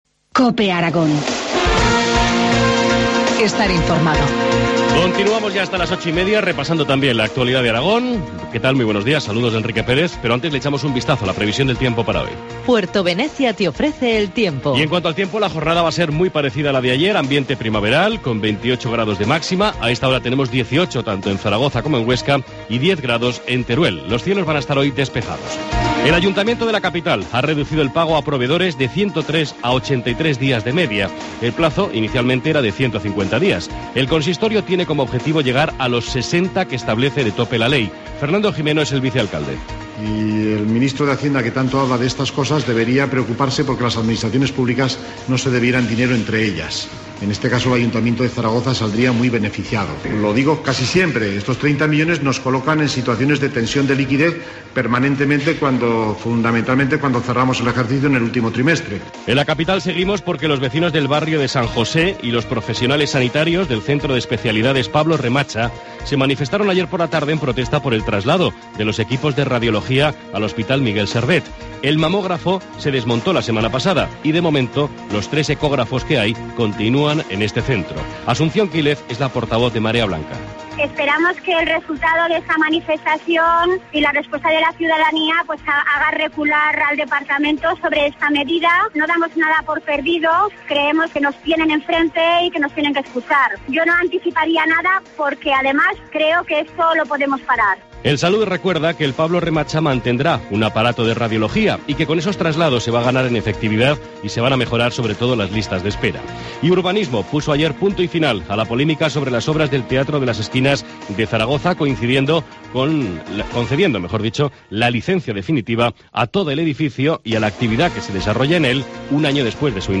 Informativo matinal, viernes 18 de octubre, 8.25 horas